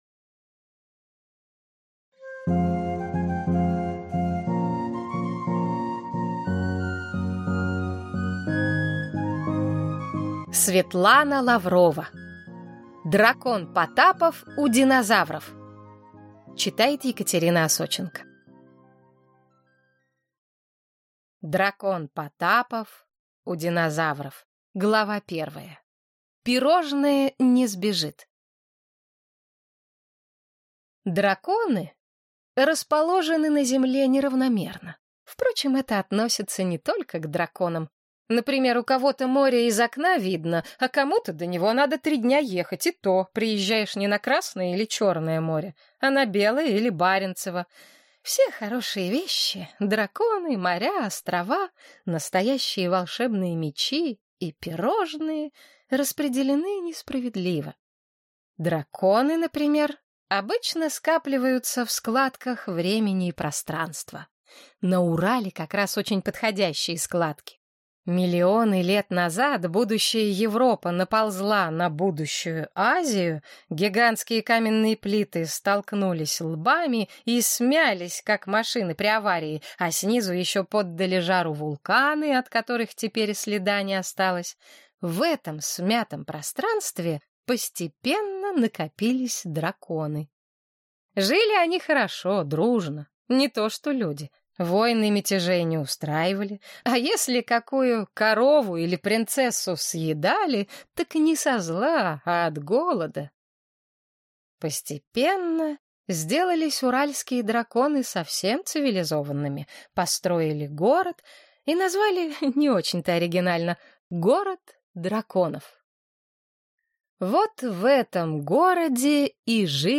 Аудиокнига Дракон Потапов у динозавров | Библиотека аудиокниг